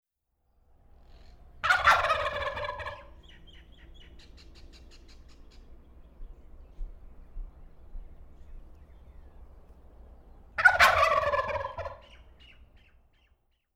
Звуки индюков
Голос дикого индюка